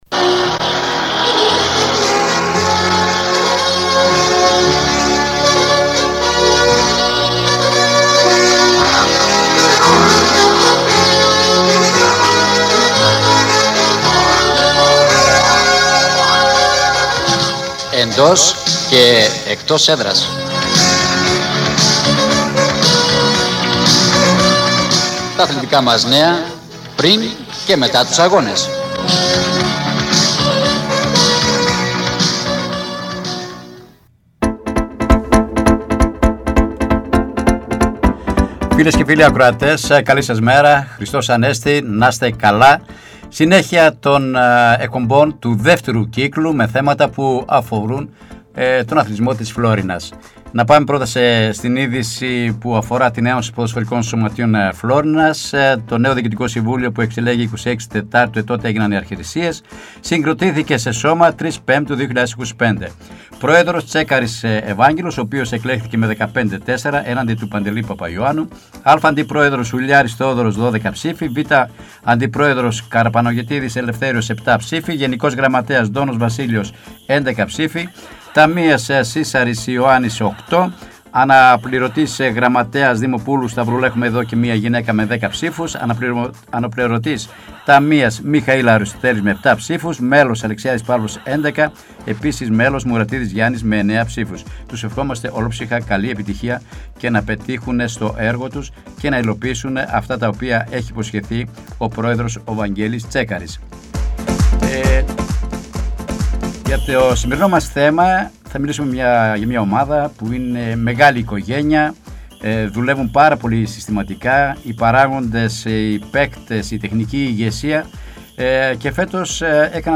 “Εντός και Εκτός Έδρας” Εβδομαδιαία αθλητική εκπομπή με συνεντεύξεις και ρεπορτάζ της επικαιρότητας, στην περιφερειακή Ενότητα Φλώρινας.